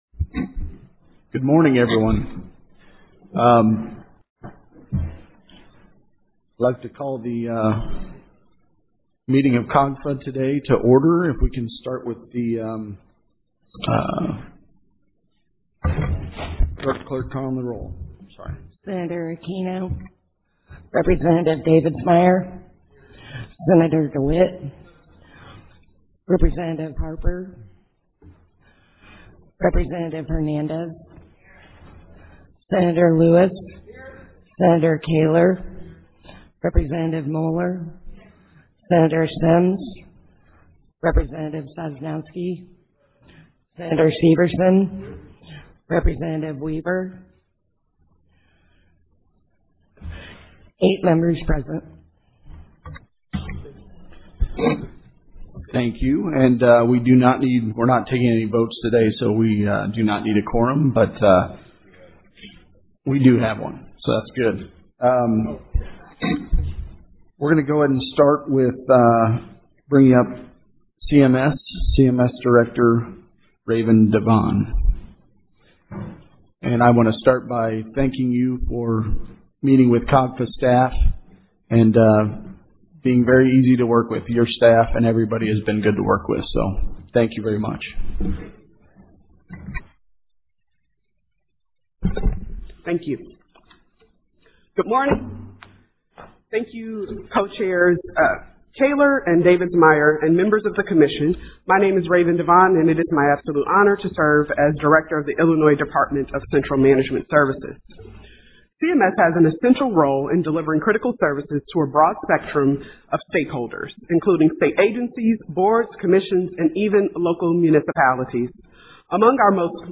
Meeting audio may be downloaded by using a right click on the audio icon and "Saving As".